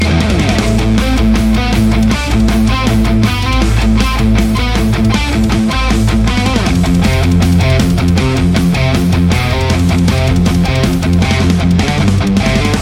This one does it with great balance and alot of bite!
Metal Riff Mix
RAW AUDIO CLIPS ONLY, NO POST-PROCESSING EFFECTS
Hi-Gain